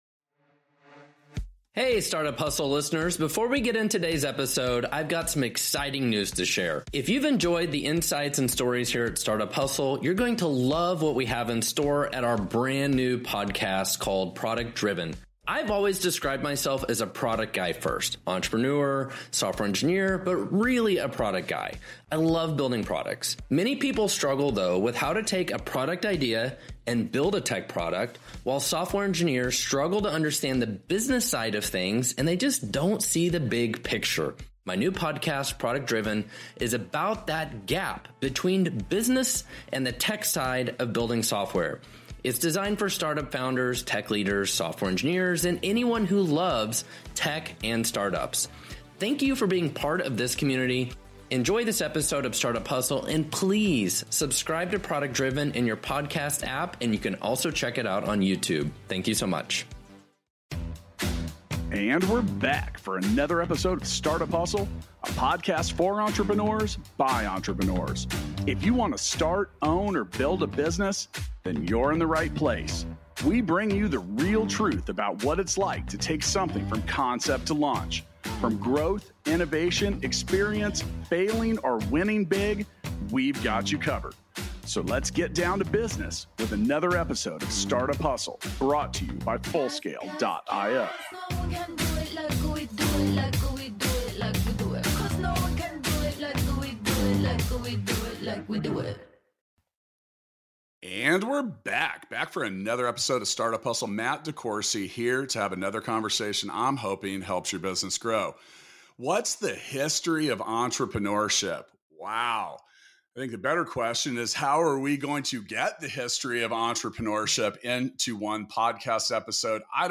This insightful conversation will help you gain a better understanding of the top traits that entrepreneurs need to possess in order to succeed.